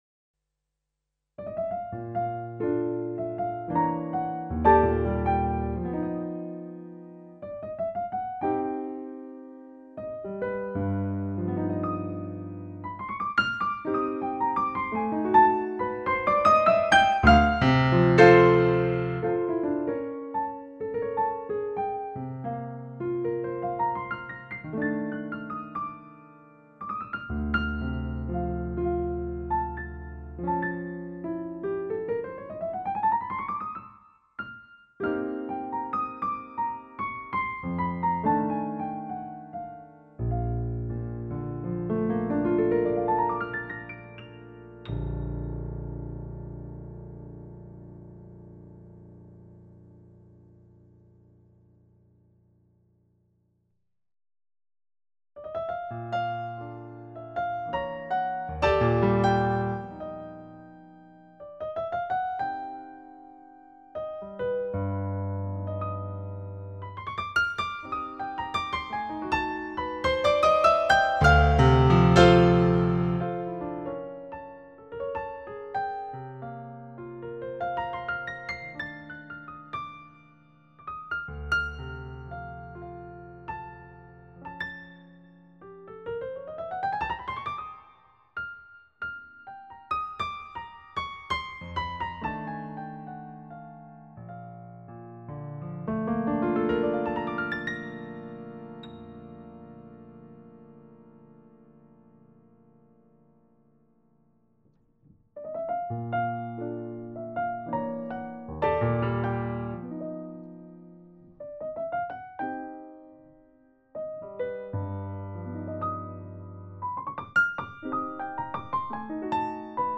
comparativa-pianoforti.mp3